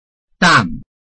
臺灣客語拼音學習網-客語聽讀拼-饒平腔-鼻尾韻
拼音查詢：【饒平腔】dam ~請點選不同聲調拼音聽聽看!(例字漢字部分屬參考性質)